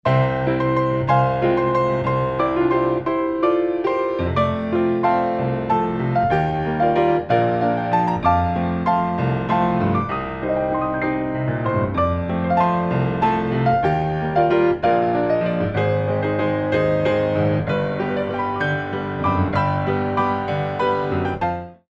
29 Original Piano Pieces for Ballet Class
Grand Allegro
mod. 3/4 - 1:08